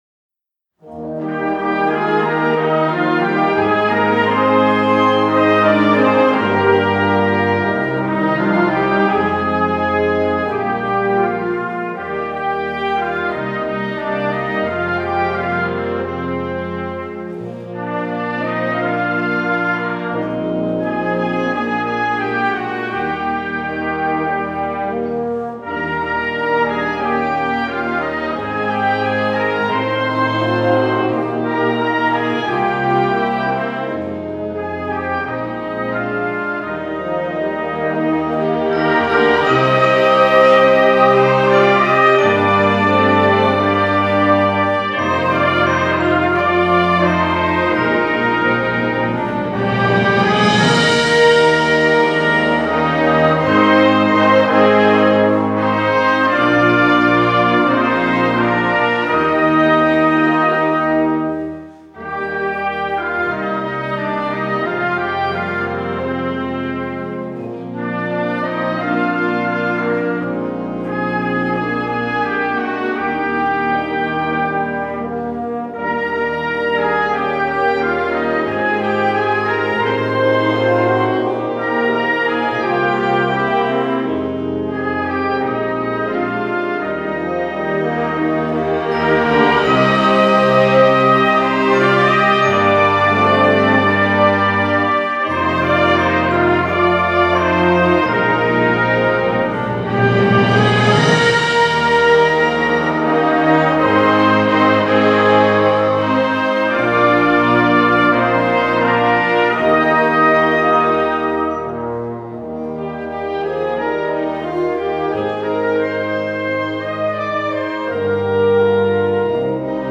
吹奏楽バージョン